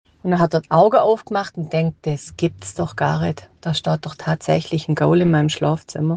im Bodensee-Alemannisch (eine Art Schwäbisch)